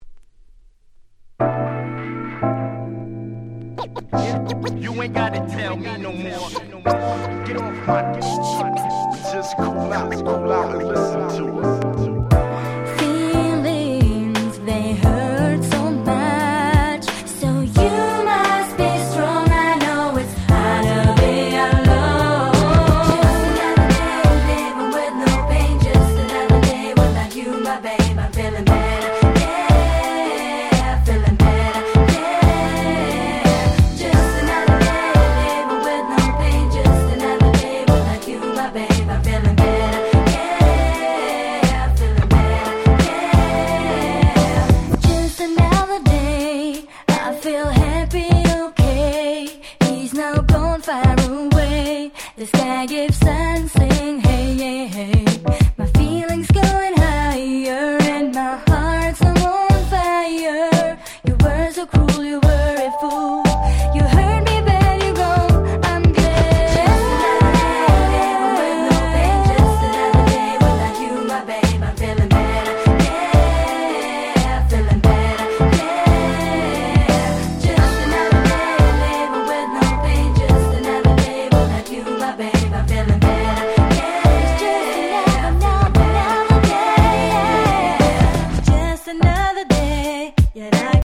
00' Nice Europe R&B !!